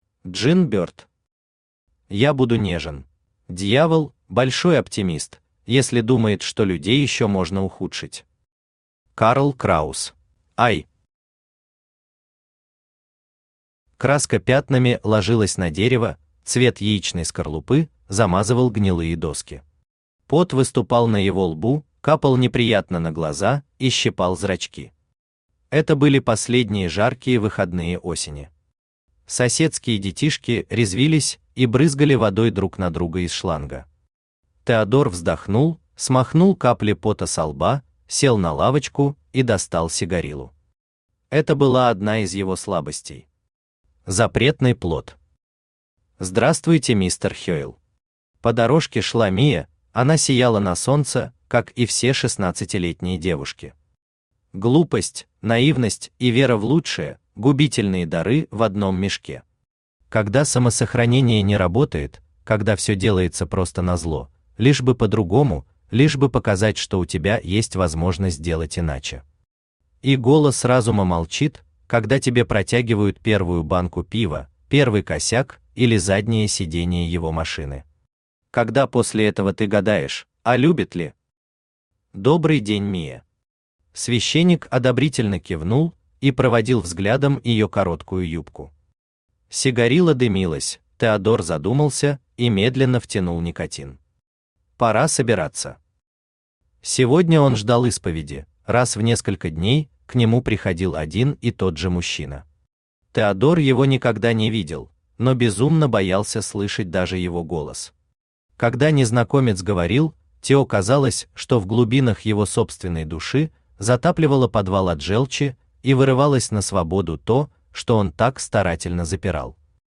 Аудиокнига Я буду нежен | Библиотека аудиокниг
Aудиокнига Я буду нежен Автор Джин Бёрд Читает аудиокнигу Авточтец ЛитРес.